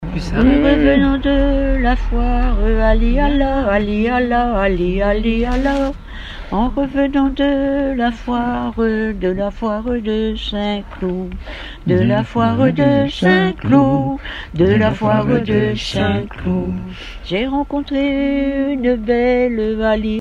Genre laisse
Témoignages et chansons
Pièce musicale inédite